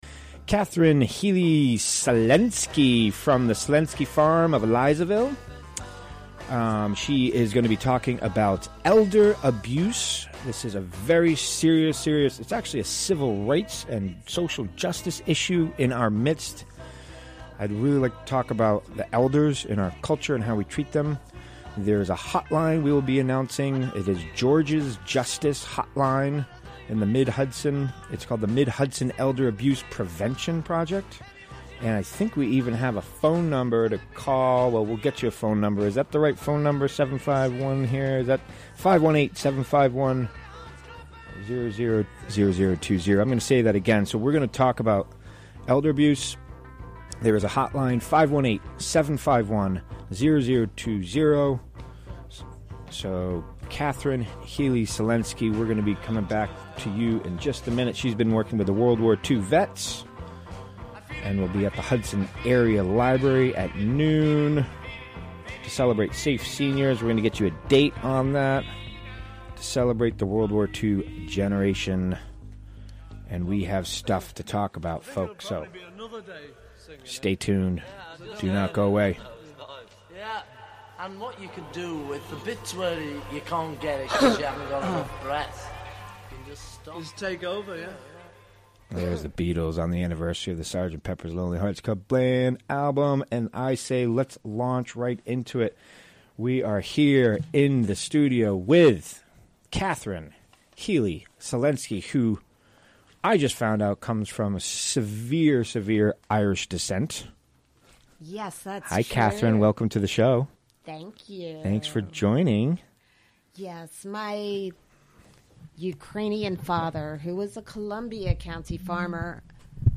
Recorded during the WGXC Afternoon Show Monday, June 5, 2017.